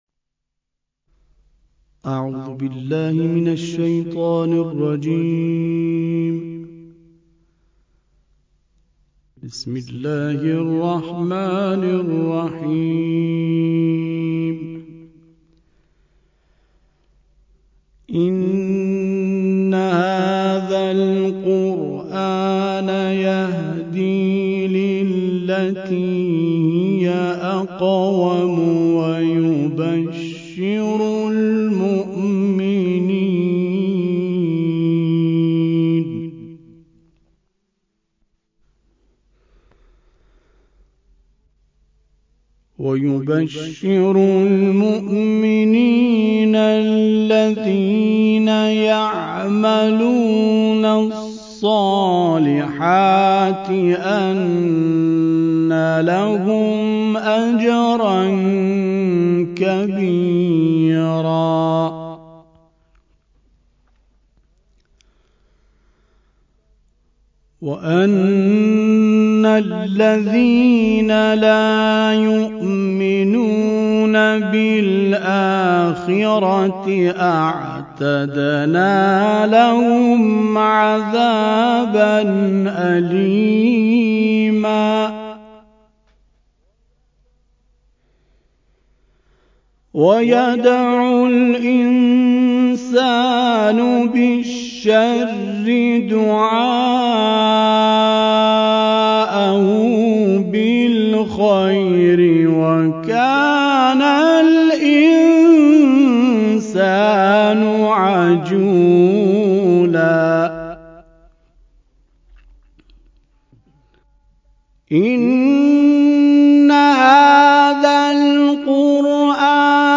در پایان تلاوتی از  سوره اسراء آیات 9 الی 15 ارائه می‌شود.